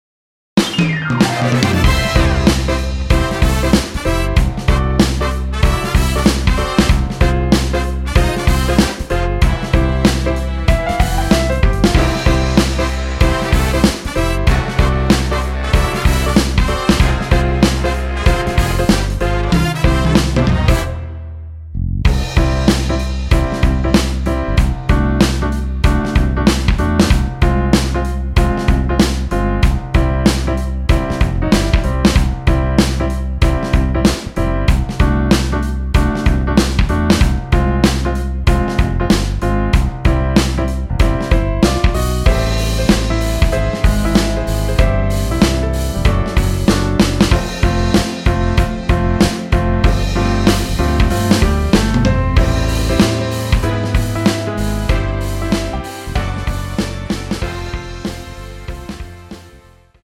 원곡보다 짧은 MR입니다.(아래 재생시간 확인)
원키에서(-2)내린 (1절앞+후렴)으로 진행되는 MR입니다.
앞부분30초, 뒷부분30초씩 편집해서 올려 드리고 있습니다.
중간에 음이 끈어지고 다시 나오는 이유는